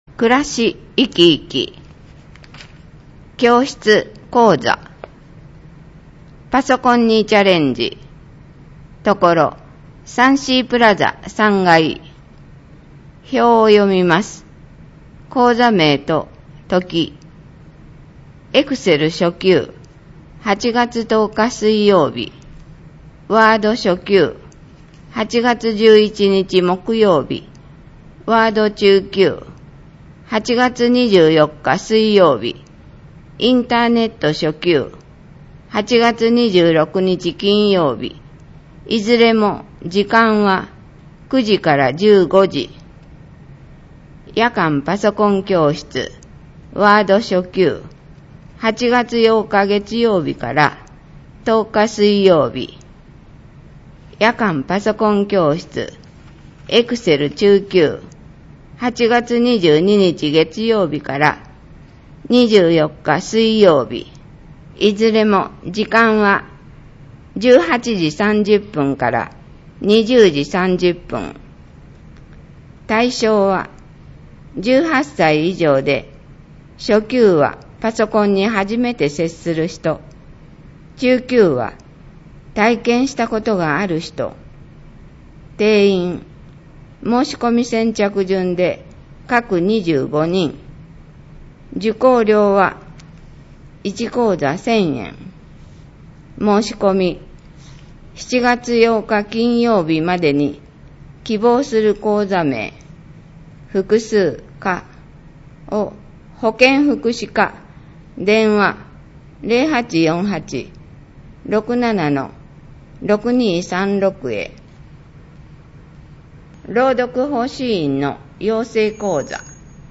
ページ タイトル 声の広報 (MP3ファイル) 全ページ／29MB １ ●表紙 約3分／796ＫＢ ２－３ ●特集 １０月１日からきれいな三原まちづくり条例がスタート 約9分／2.2ＭＢ ４－５ ●帝人用地と駅前東館跡地の活用の方向性 約9分／2.2ＭＢ ６ ●市政フラッシュ 約5分／1.2ＭＢ ７ ●観光写真コンテスト 作品の募集 ●今月スタート！